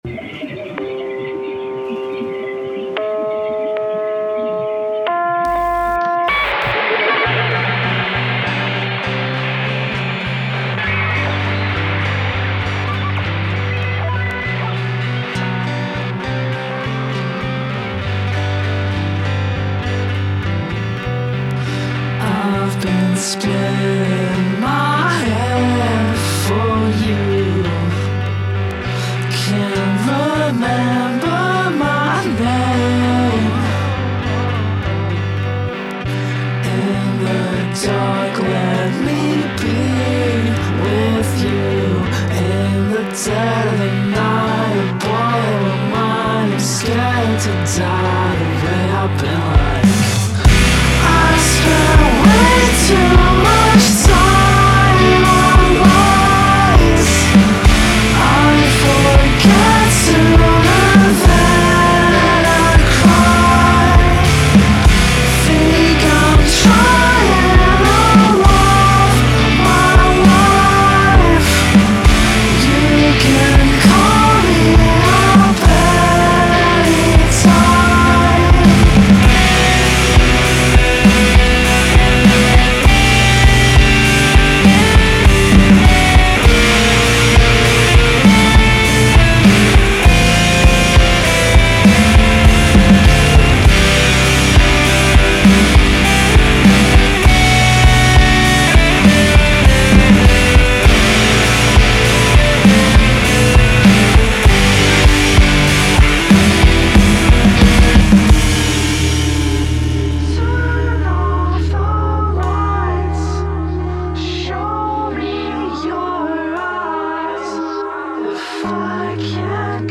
alternative  United States